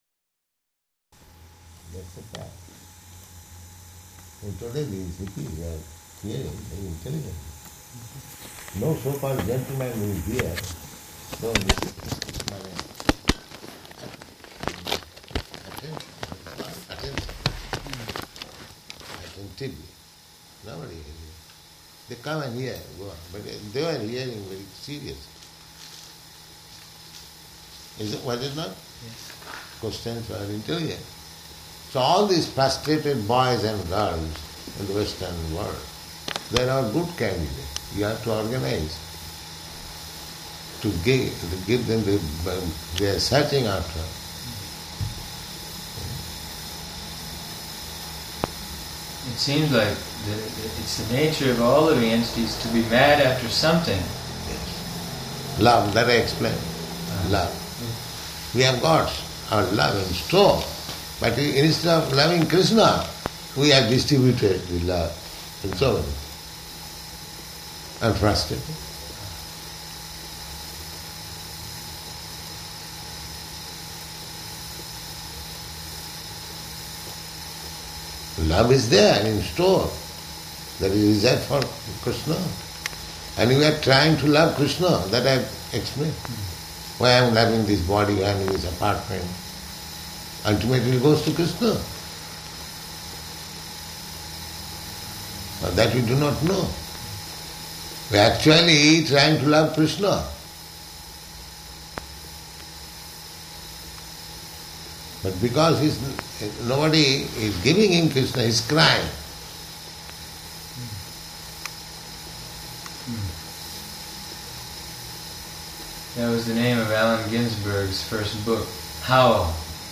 Room Conversation
Room Conversation --:-- --:-- Type: Conversation Dated: April 2nd 1972 Location: Sydney Audio file: 720402R1.SYD_Qlc6f44.mp3 Prabhupāda: That's a fact.